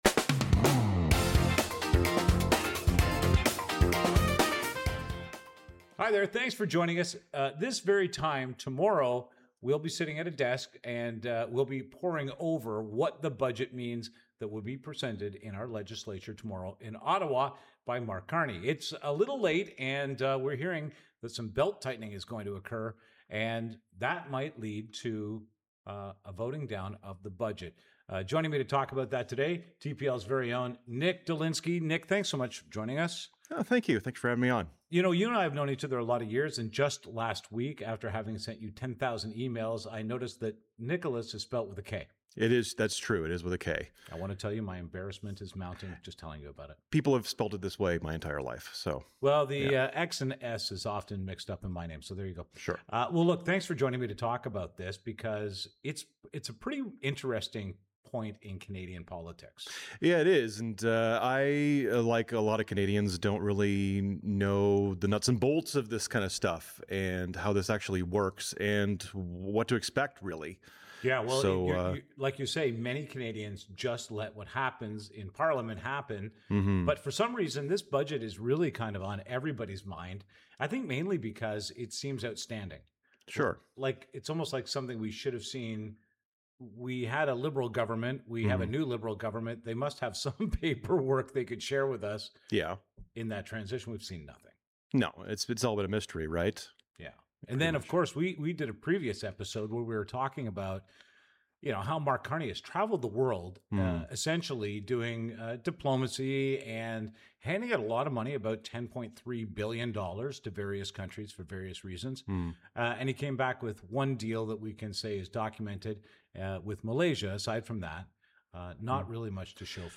… continue reading 25 episodes # Daily News # Politics # News Talk # News # True Patriot Love